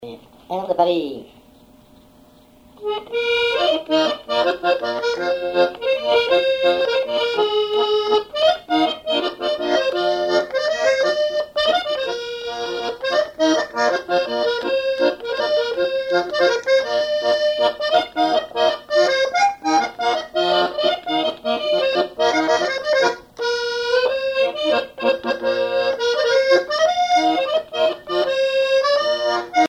Mémoires et Patrimoines vivants - RaddO est une base de données d'archives iconographiques et sonores.
accordéon(s), accordéoniste
danse : java
Pièce musicale inédite